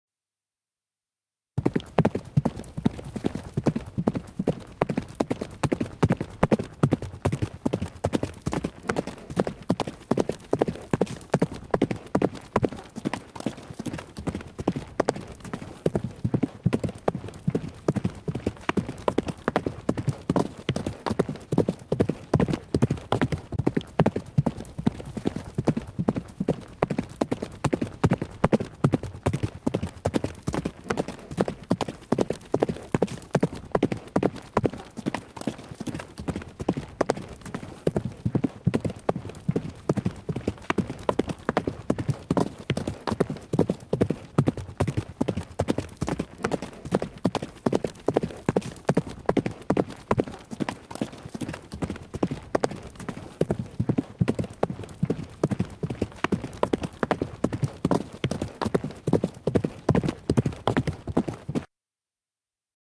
Horse Gallop Sound Effect freesound (320 kbps)
Category: Sound FX   Right: Personal